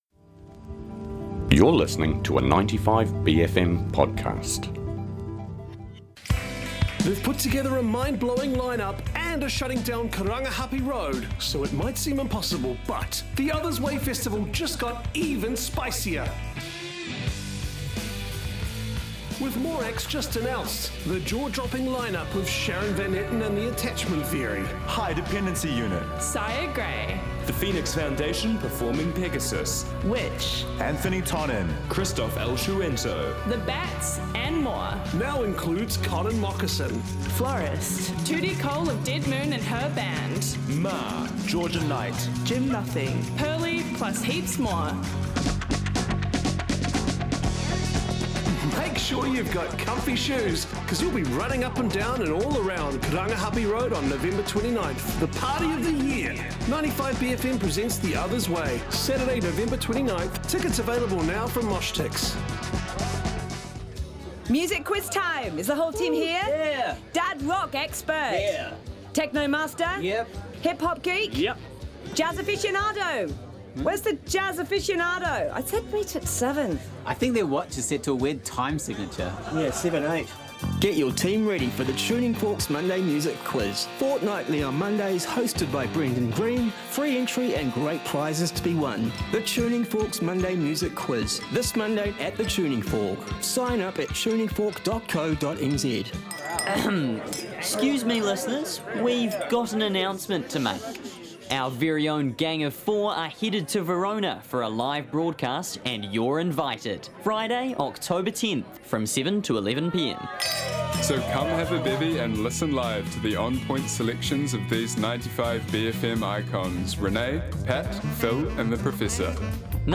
Black metal Bohdran, Modern medical machines of Scottish Hospitals & ancient wax cylinder home recordings including someone whistling for a dog while someone else plays a trumpet recorded in the late 1890's! Recordings from disability support service music programs from London, Wellington, Adelaide, & Melbourne.
Blind guitar evangelists, grindcore sung by pitbulls & a crooning undertaker who survived a plane crash & 50 skingraft surgeries.
Communities, ceremonies & field recordings.